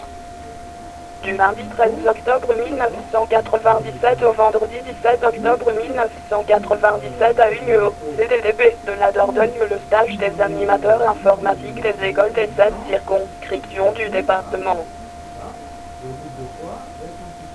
L'ordinateur vous parle